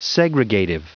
Prononciation du mot segregative en anglais (fichier audio)
Prononciation du mot : segregative